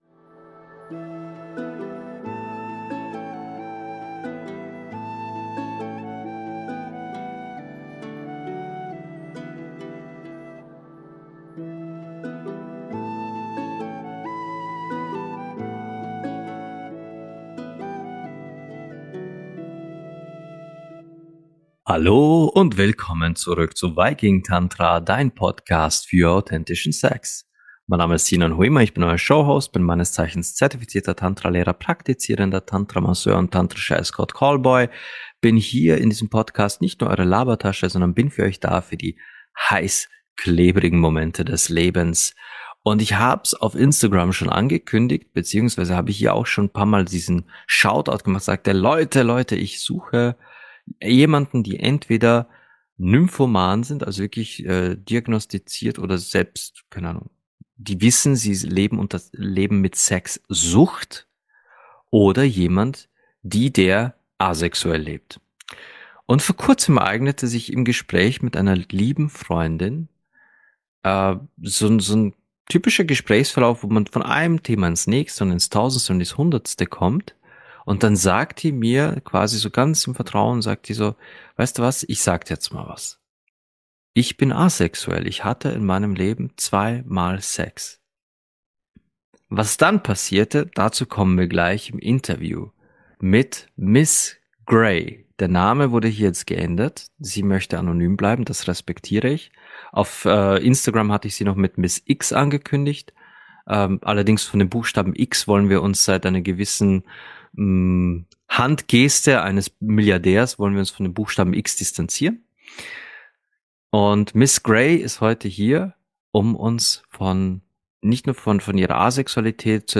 Asexualität im Interview